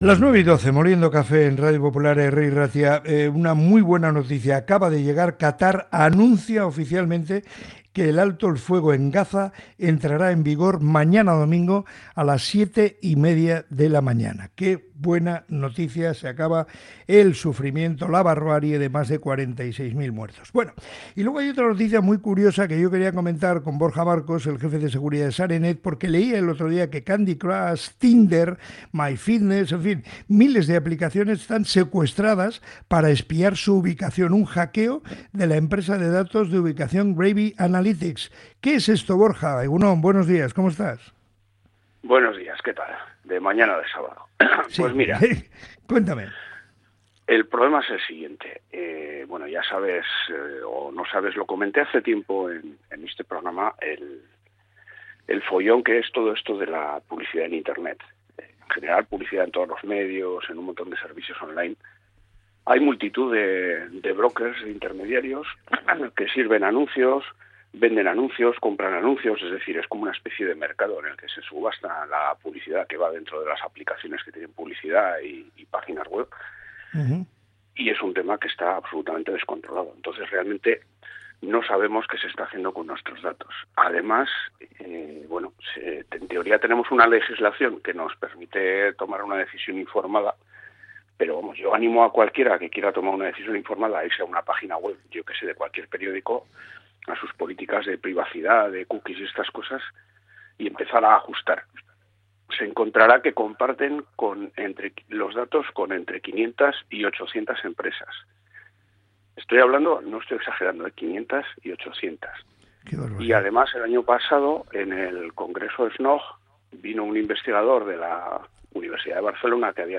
En el programa Moliendo Café de Radio Popular – Herri Irratia, se ha destacado un problema cada vez más preocupante: la falta de control sobre los datos de localización recopilados por aplicaciones móviles. Aplicaciones populares como Candy Crush, Tinder o MyFitness han sido involucradas en un hackeo masivo a la empresa de datos de ubicación Gravy Analytics.